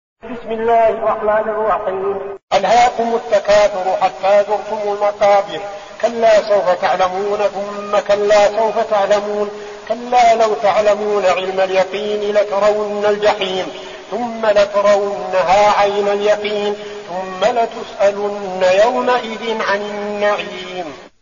المكان: المسجد النبوي الشيخ: فضيلة الشيخ عبدالعزيز بن صالح فضيلة الشيخ عبدالعزيز بن صالح التكاثر